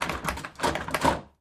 На этой странице собраны реалистичные звуки ручки двери: скрипы, щелчки, плавные и резкие повороты.
Попытка открыть дверь оказалась неудачной